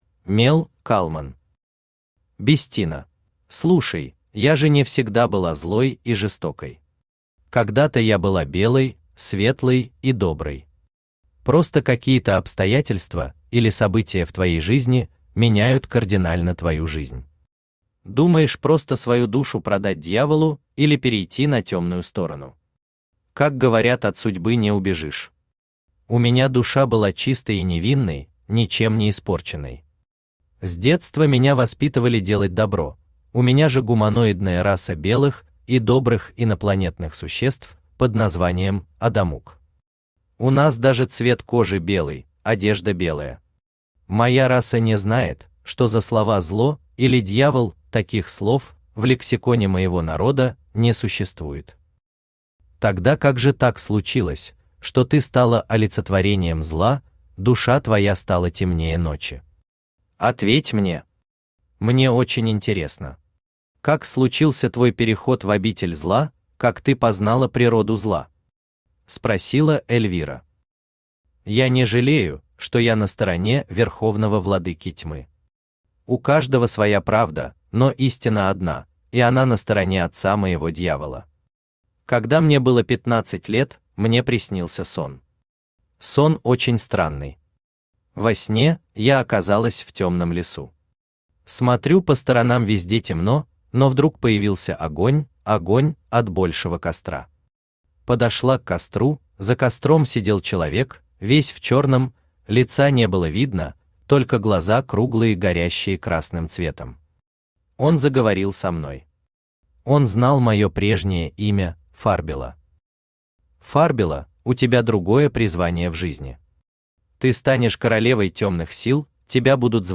Аудиокнига Бестина | Библиотека аудиокниг
Aудиокнига Бестина Автор Мел Калман Читает аудиокнигу Авточтец ЛитРес.